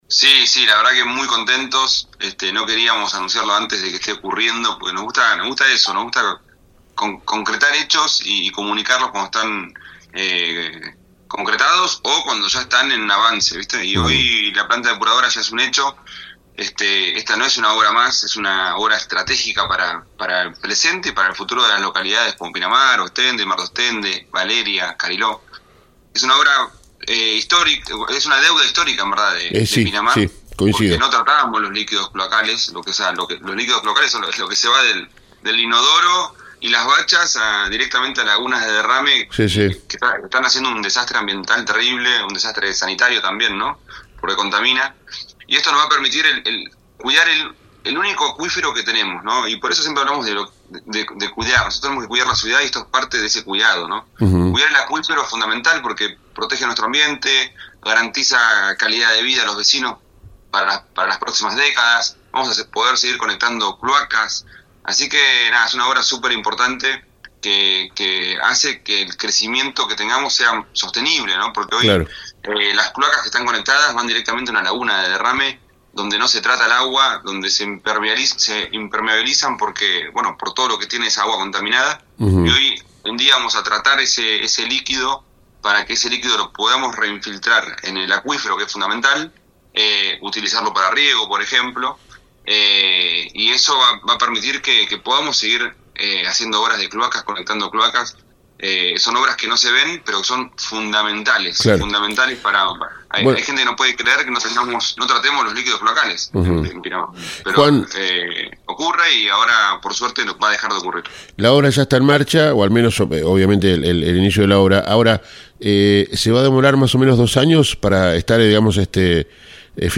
El intendente Ibarguren hablo con Pinamardiario este martes y dio detalles de la obra de la planta depuradora que inició en Pinamar y que tendra una duración de dos años, Ibarguren destacó que es la primer obra publica de la gestión Milei en el país. Además el funcionario explicó que esta es una deuda histórica que la dirigencia política tiene con los vecinos de Pinamar .